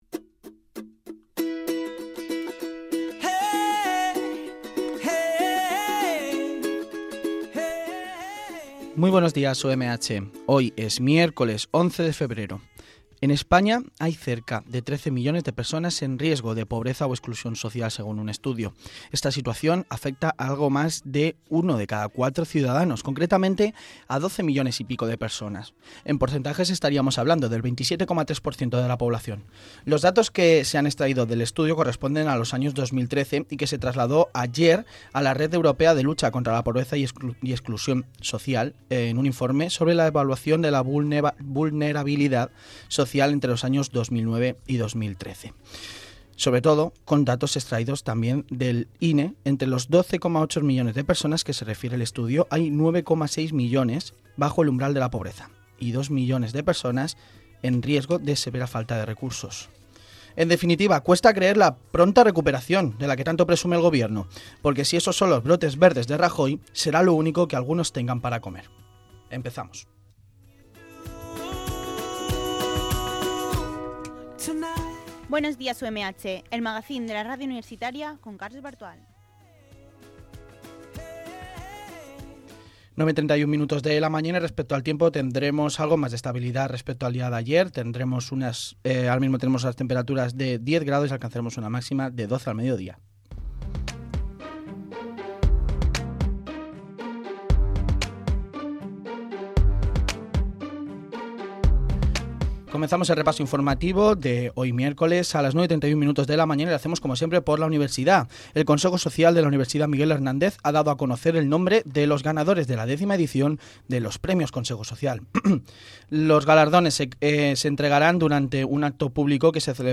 Magacín diario que incluye noticias de la actualidad informativa y secciones elaboradas por estudiantes de la titulación de Periodismo de la UMH.